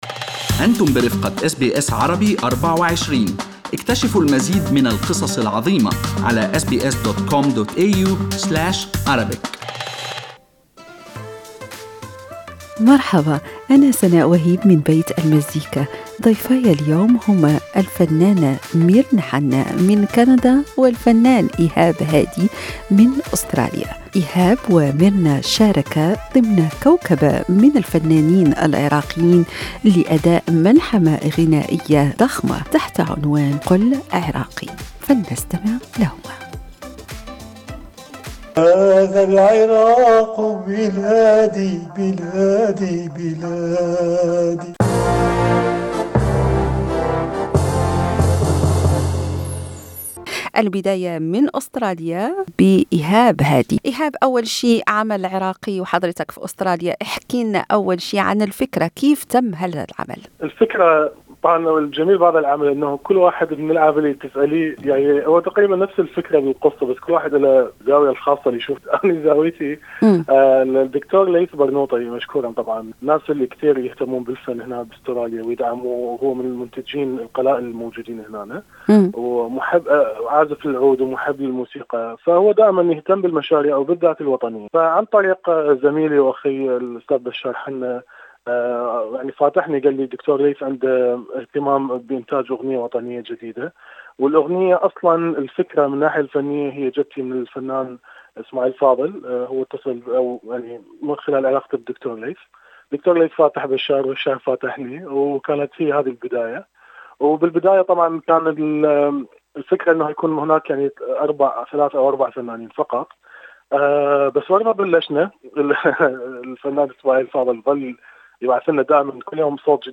وفي لقاء مع برنامج لبيت المزيكا